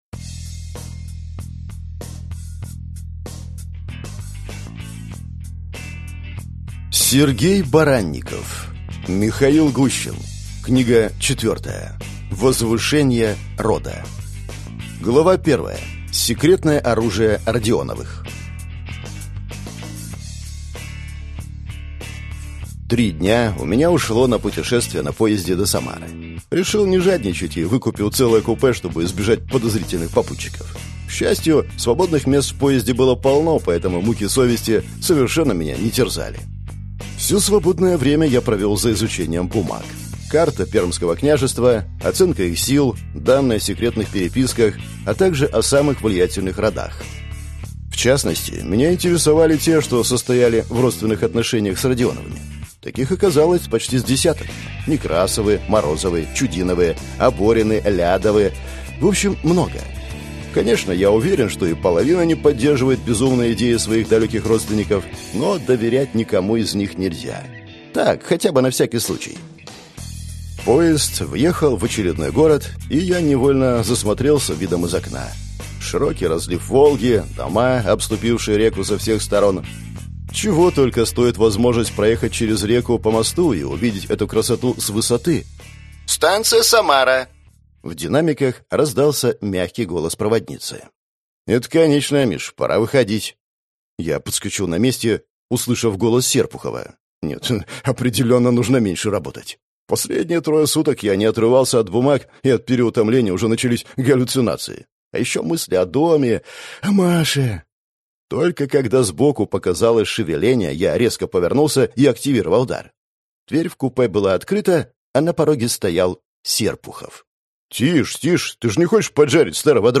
Аудиокнига Михаил Гущин. Книга 4. Возвышение рода | Библиотека аудиокниг